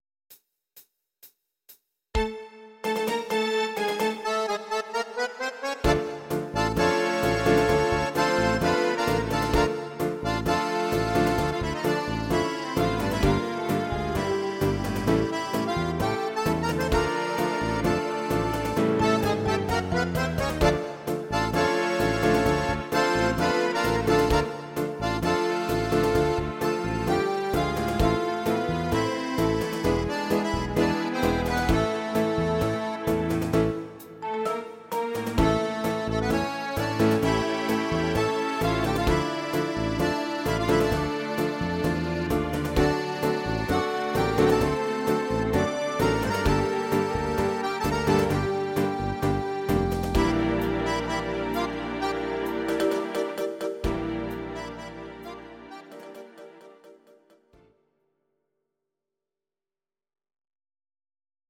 (instr.)